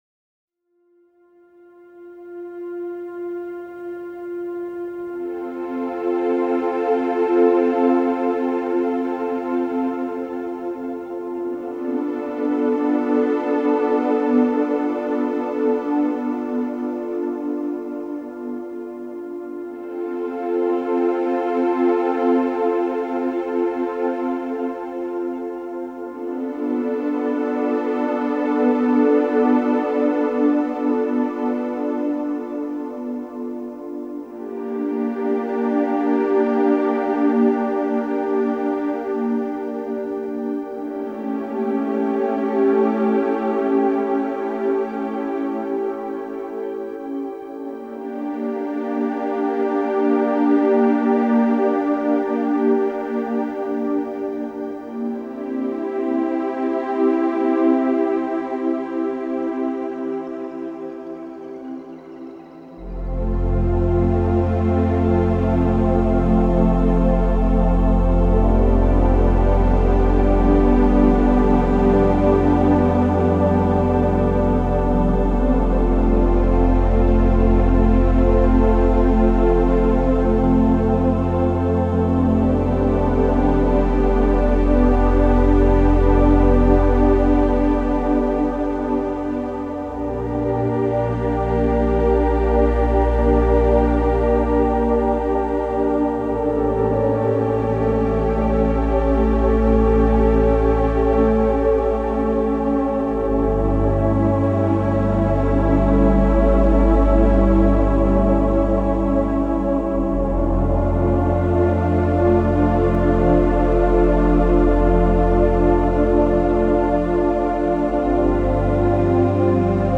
filled with gentle extended soundscapes